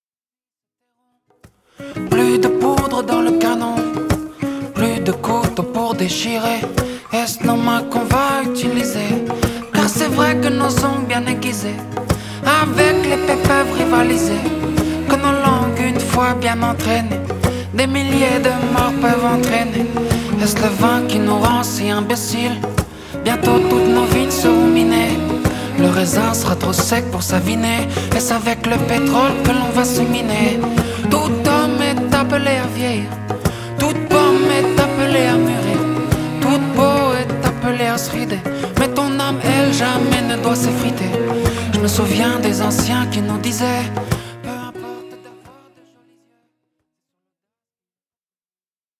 reggae roots